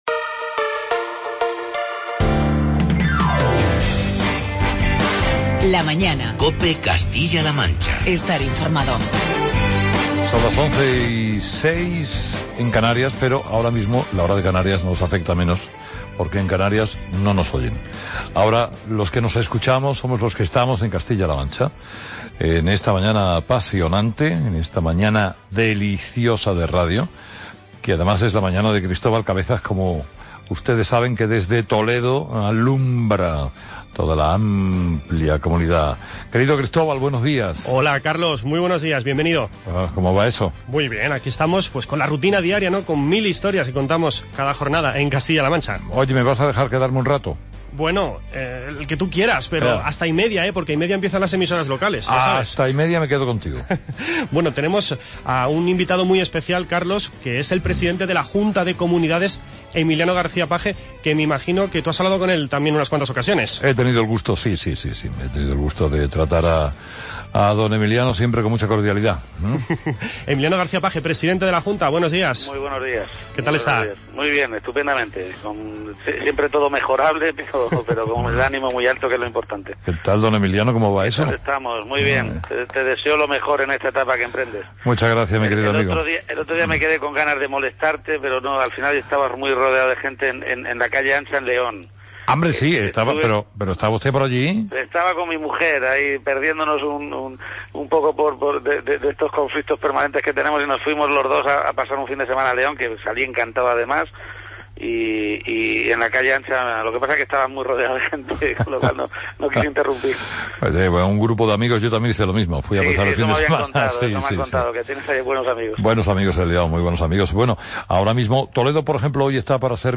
Carlos Herrera participa este martes en nuestro magacine en el que durante unos minutos conversamos con...